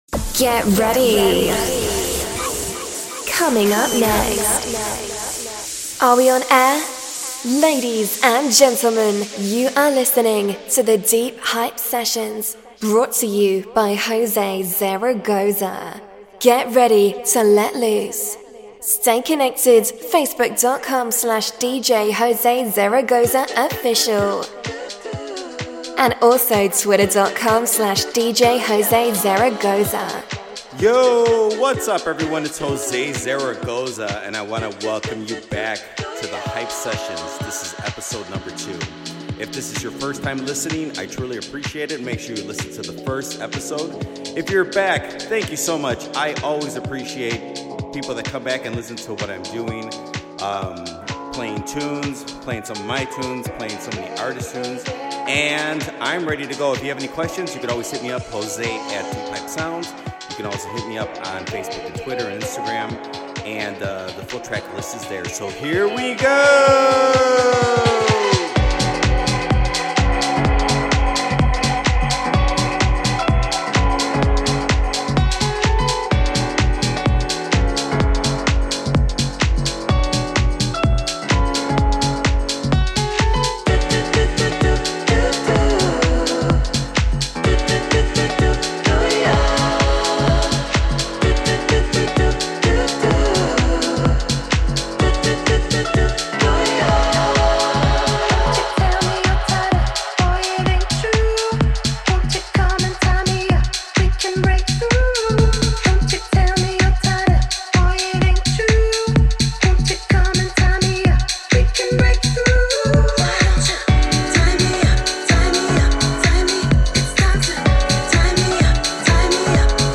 the finest dance music